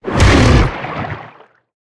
Sfx_creature_snowstalker_flinch_swim_01.ogg